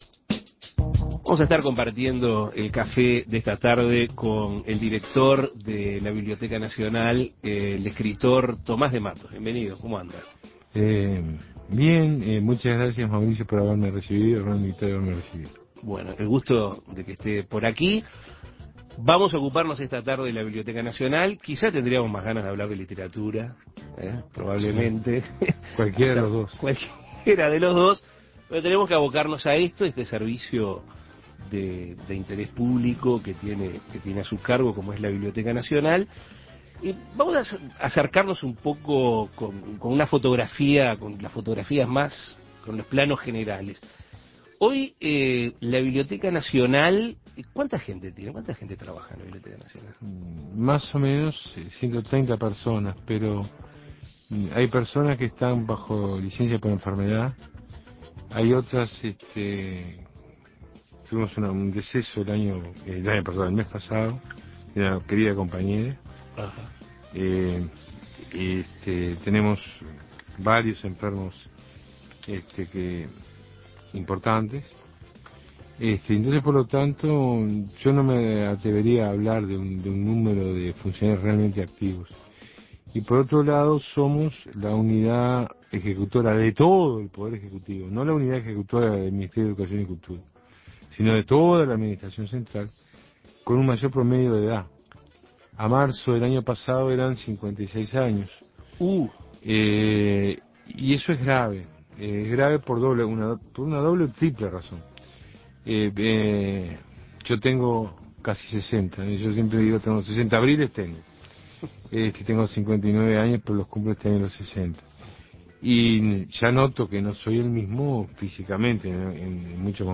Escuche la entrevista a Tomás de Mattos